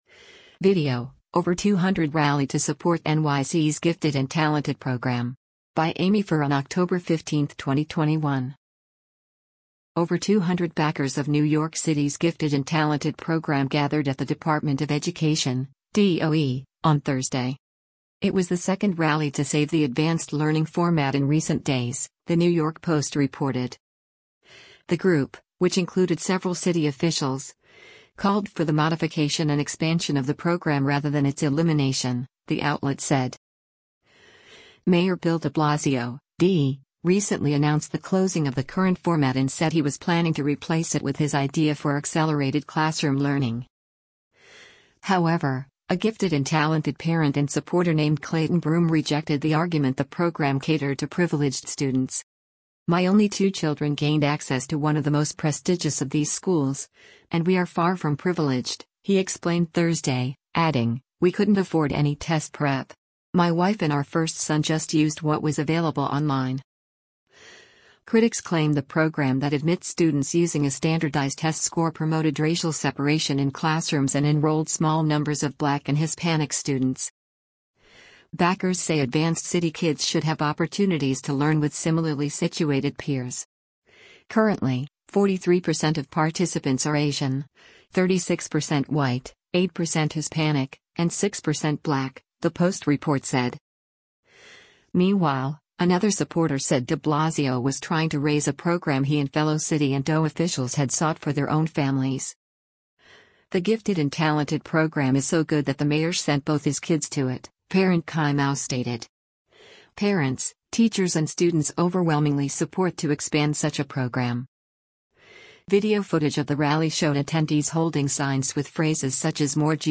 Over 200 backers of New York City’s Gifted and Talented program gathered at the Department of Education (DOE) on Thursday.
Video footage of the rally showed attendees holding signs with phrases such as “More G&T! Not Less” written on them.